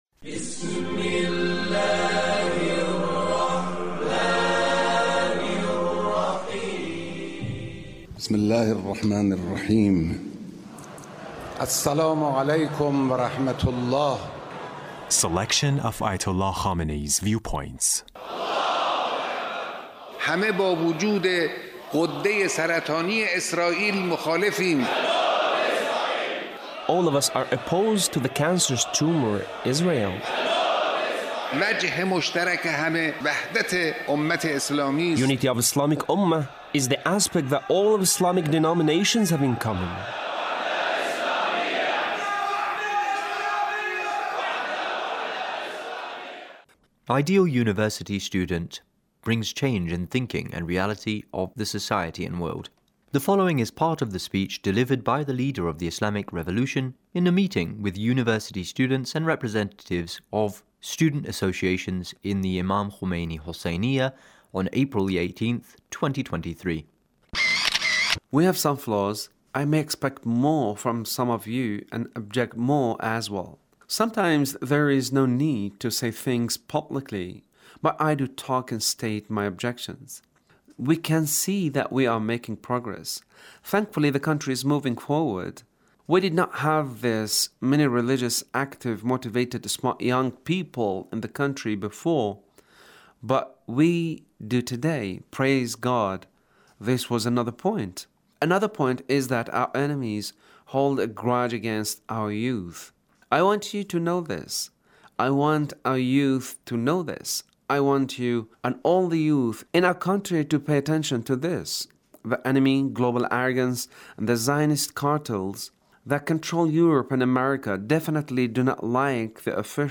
Leader's Speech with University Student